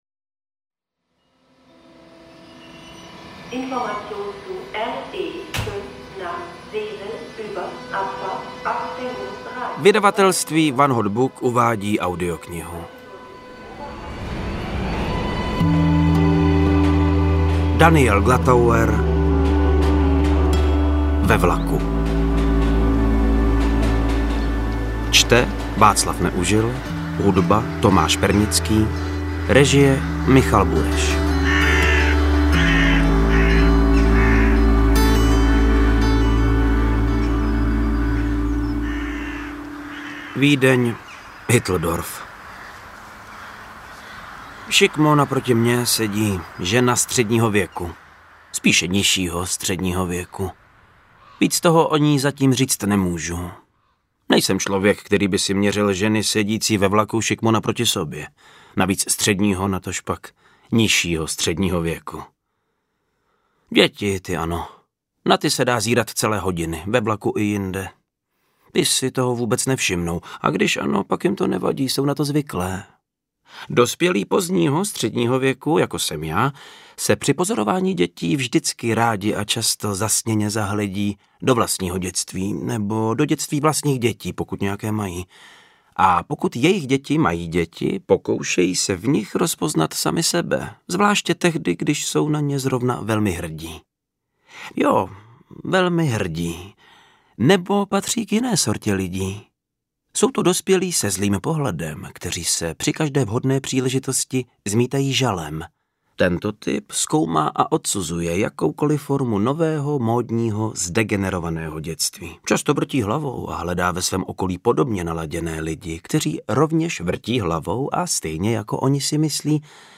Interpret:  Václav Neužil
AudioKniha ke stažení, 18 x mp3, délka 5 hod. 31 min., velikost 297,0 MB, česky